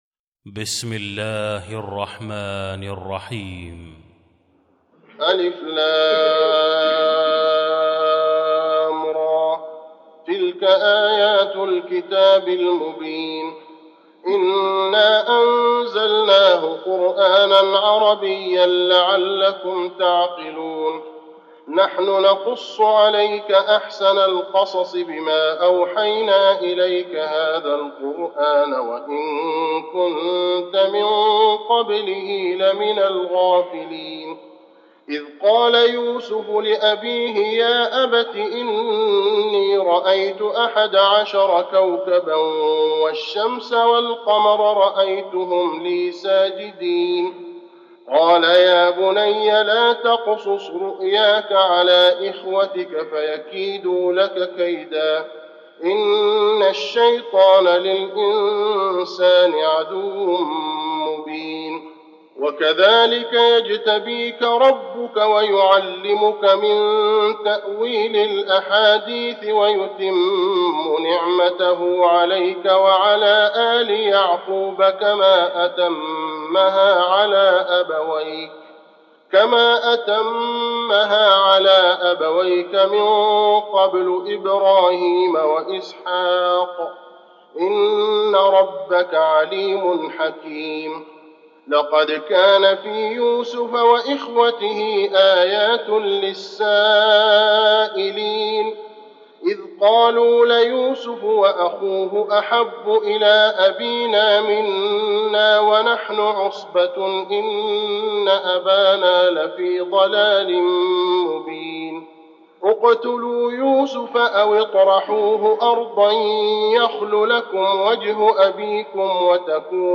المكان: المسجد النبوي يوسف